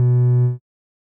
Techmino/media/sample/bass/15.ogg at 06d7a1df6b545625c441db07f6d1bc2ddc5911fb